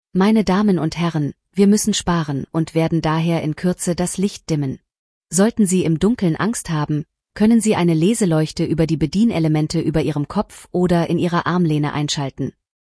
CabinDimTakeoff.ogg